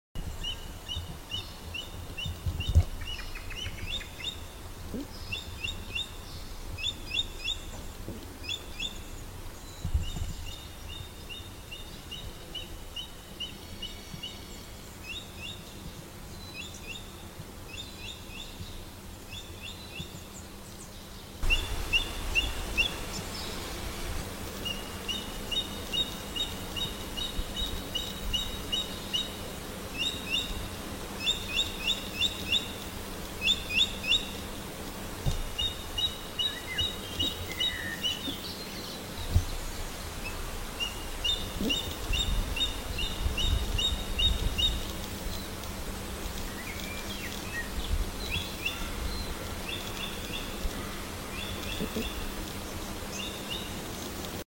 Grijze havik - Accipiter novaehollandiae
English Grey goshawk Français Autour blanc
Grijze_havik-sound.mp3